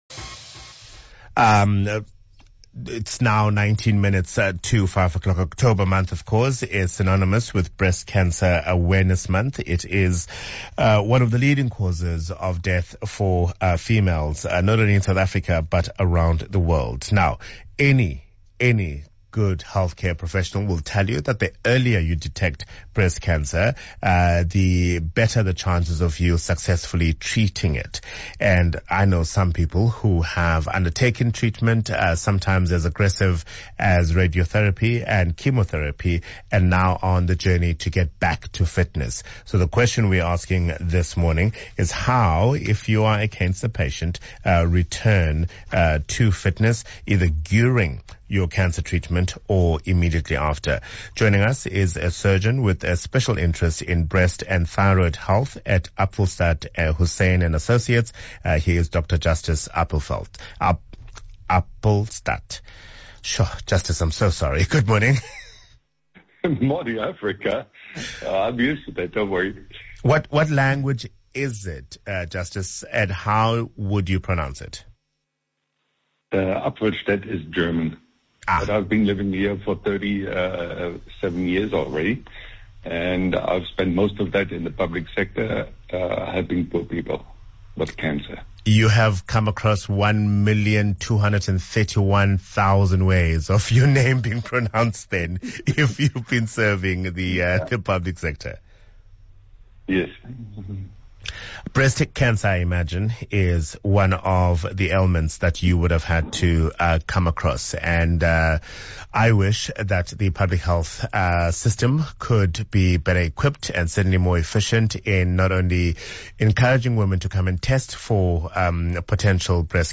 With kind permission from 702 and Cape Talk, the full interview is available to listen to below.